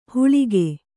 ♪ huḷige